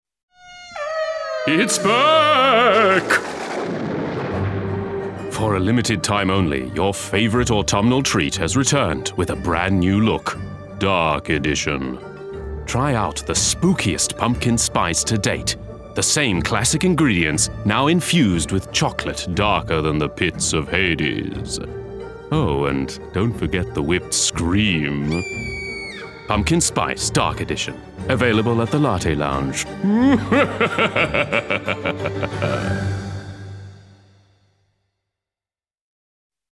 Commercial: Comic